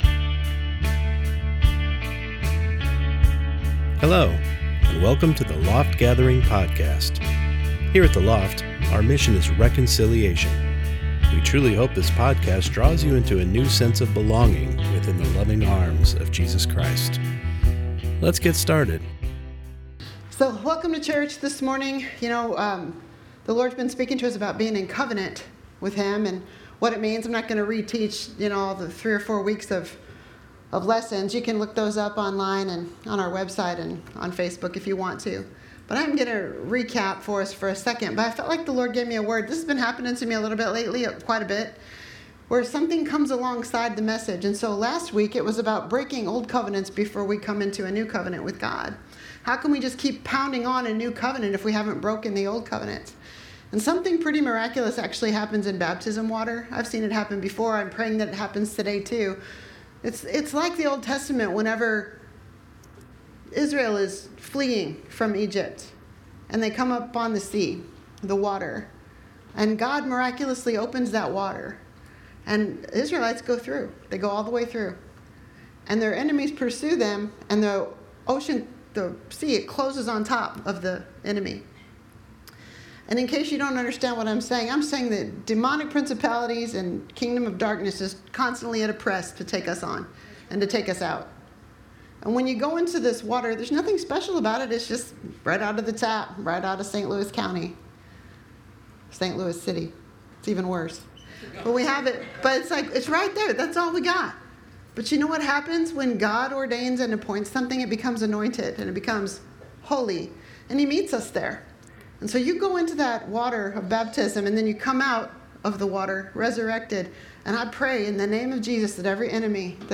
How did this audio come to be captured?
Sunday Morning Service Service